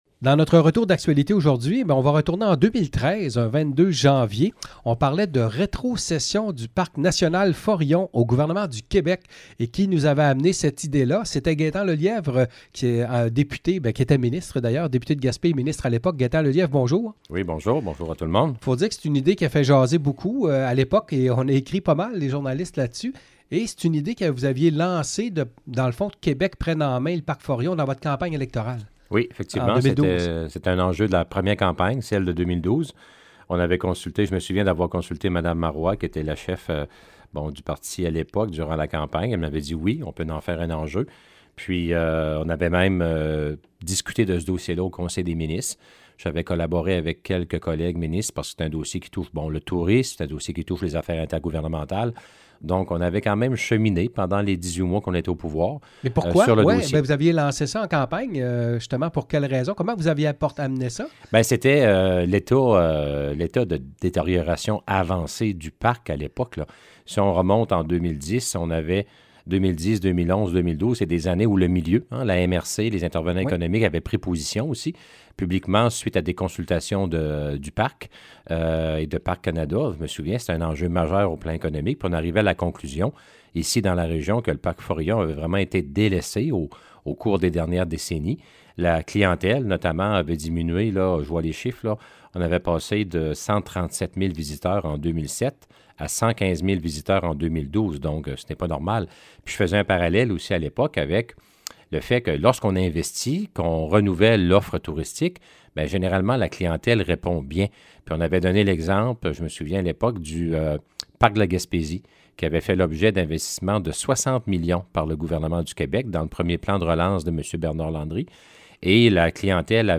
Entrevue avec Gaétan Lelièvre: